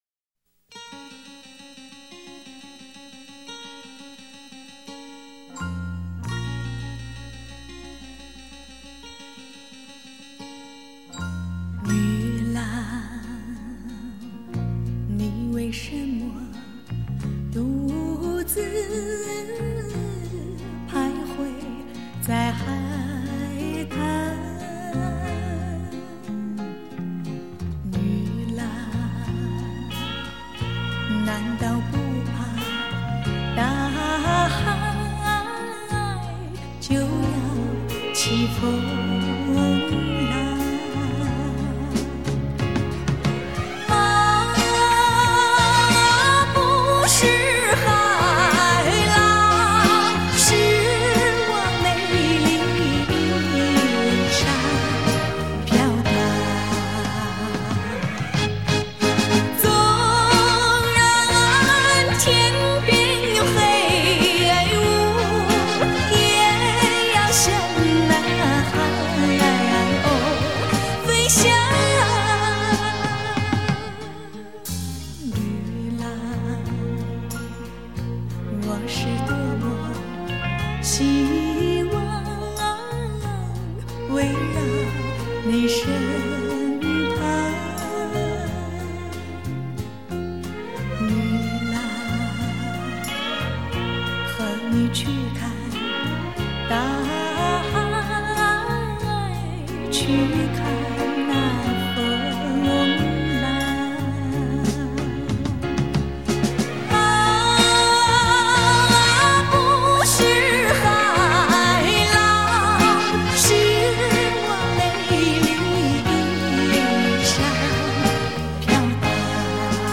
第一手原音 最原始歌声 万世珍藏精装版